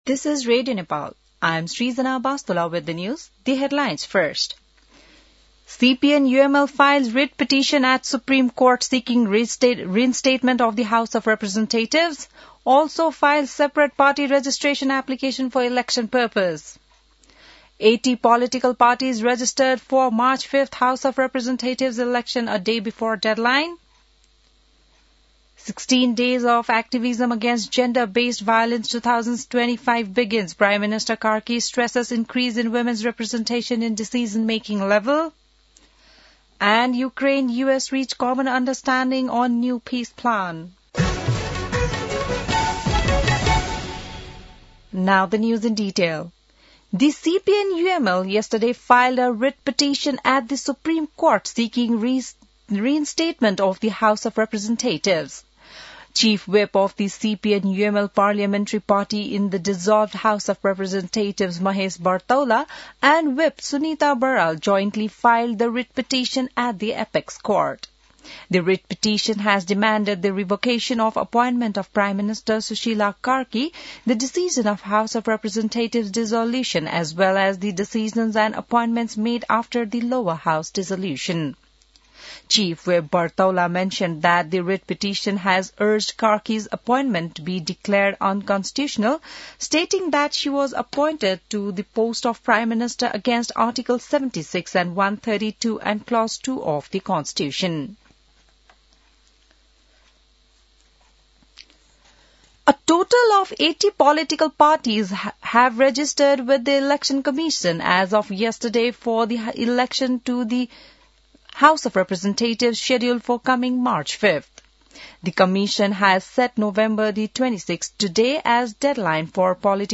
बिहान ८ बजेको अङ्ग्रेजी समाचार : १० मंसिर , २०८२